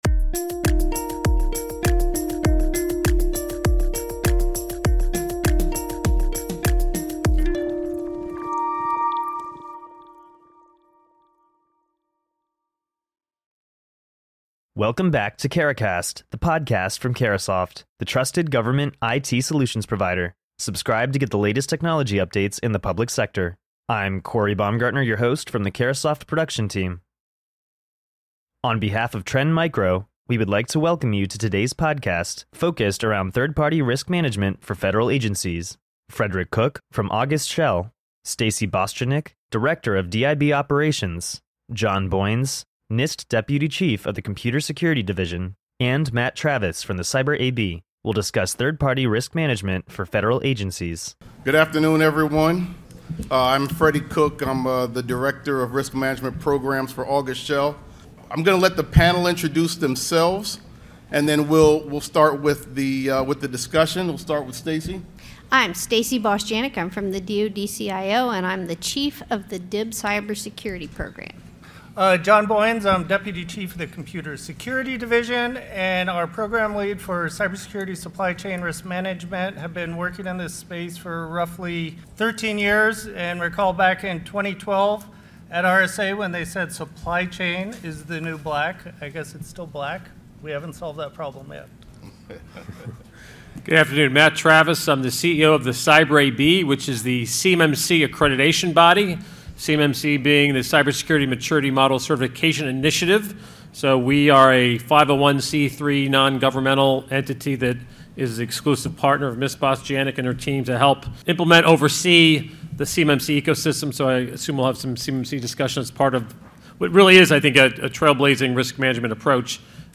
Tune into the Verge Technologies podcast to hear data experts discuss how SentientDB, an AI-powered database management solution, ensures performance, reliability and mobility in multicloud and hybrid environments. Enable your Government agency to prioritize data management, security and team development with innovative cloud convergence services.